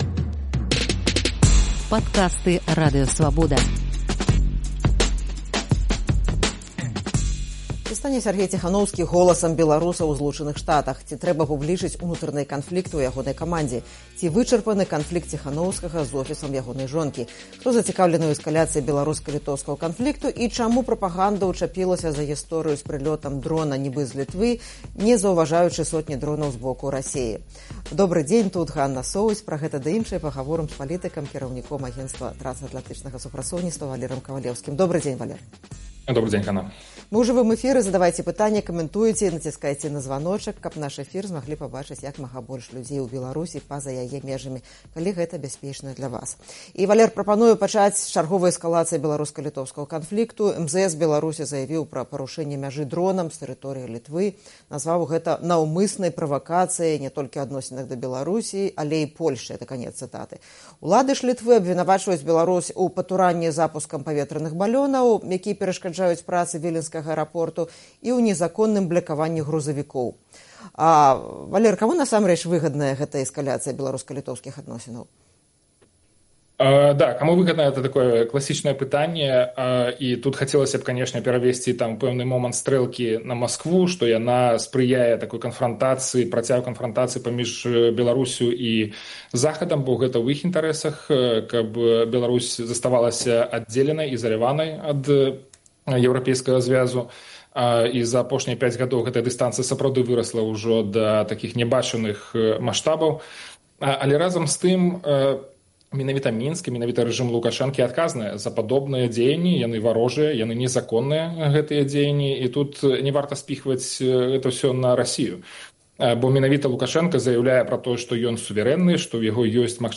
Хто зацікаўлены ў эскаляцыі беларуска-літоўскага канфлікту? Чаму прапаганда ўчапілася за гісторыю з прылётам дрона зь Літвы, не заўважаючы сотні дронаў з боку Расеі? Пра гэта ды іншае ў жывым эфіры пагаворым з палітыкам